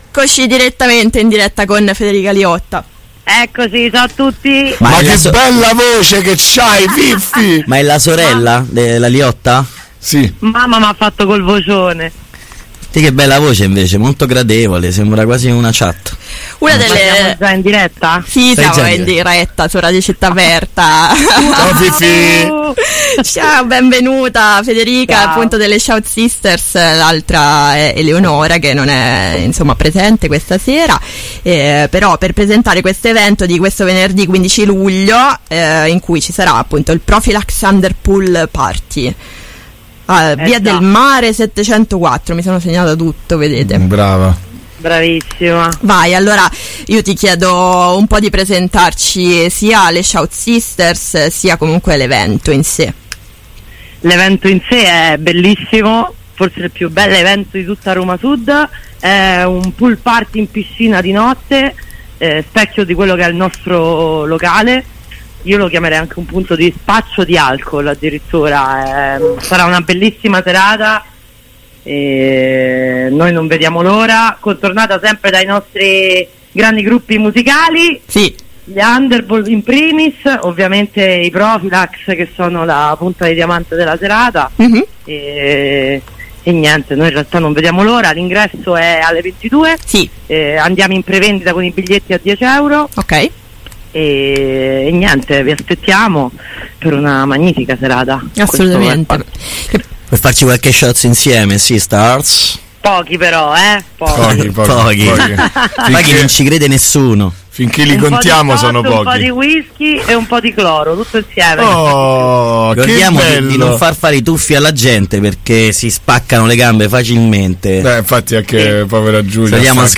intervista-prophilax-11-7-22.mp3